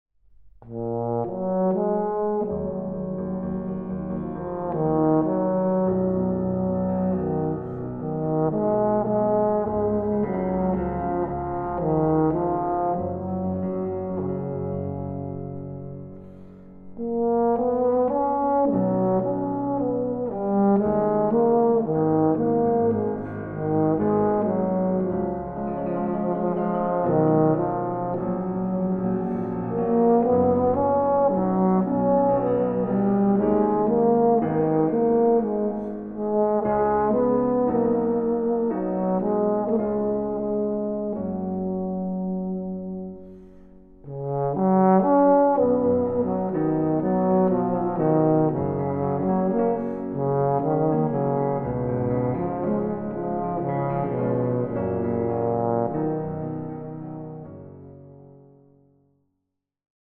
Arr. for Euphonium and Piano